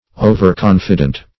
Overconfident \O"ver*con"fi*dent\, a.
overconfident.mp3